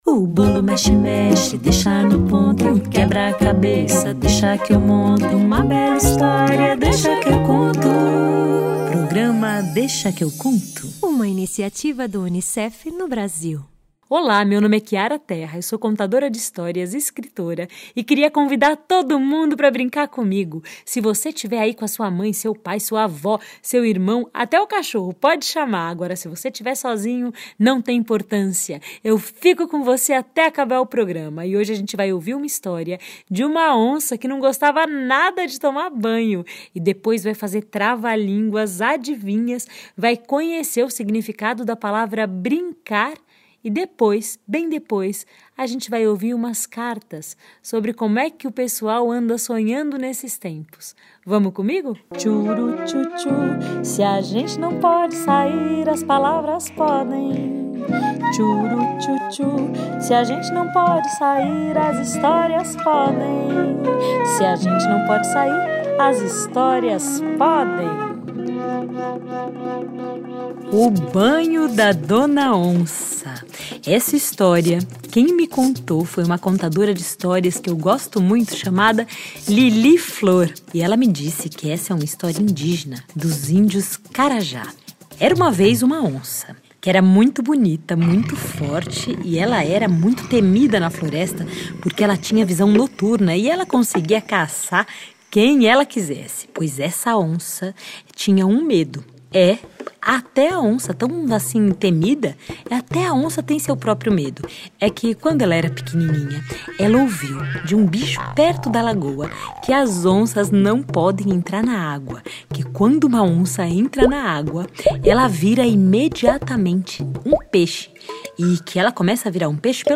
Teremos história de medo com o Lobo Mau, vamos aprender a fazer um chapéu de papel com o Euzébio e a Aurora e vamos ouvir pela primeira vez na vida uma entrevista exclusiva com ele, o Lobo Mau!